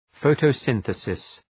Προφορά
{,fəʋtəʋ’sınɵısıs}